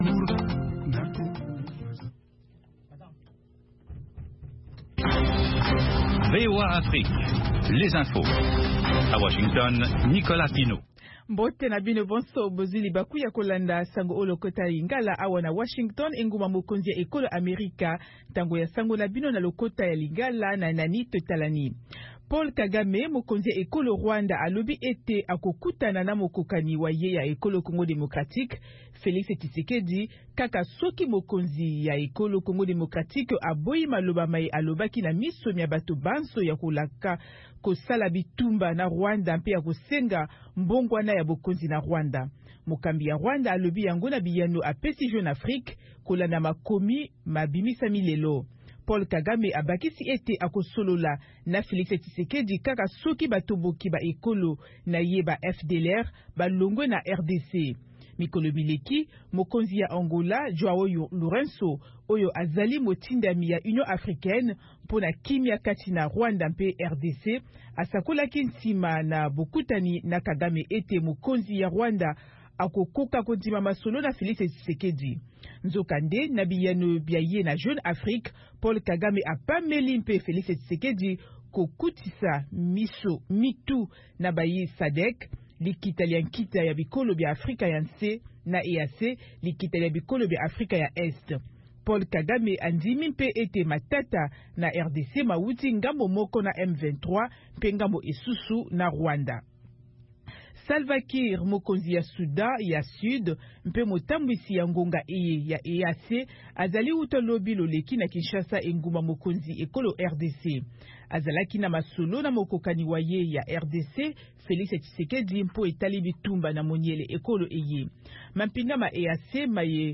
Basango na VOA Lingala